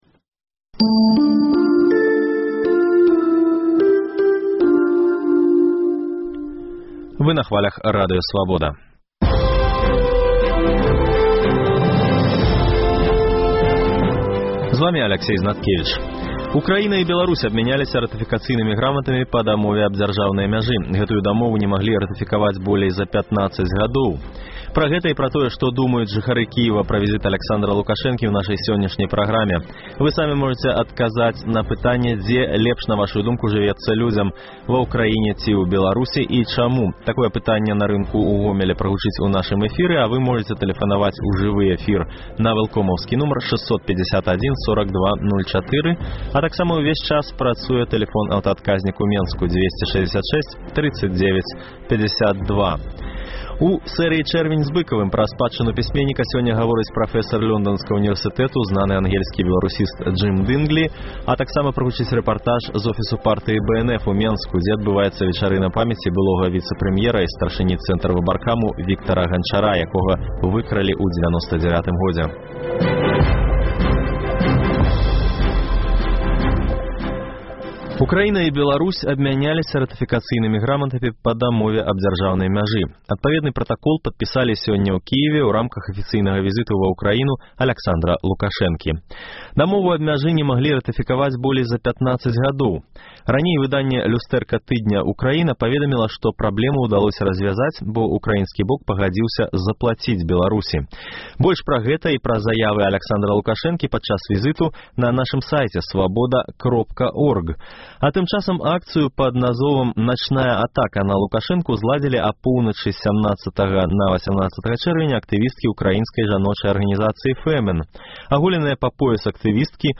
Апытаньне на рынку ў Гомелі, дзе шмат і ўкраінцаў, і беларусаў.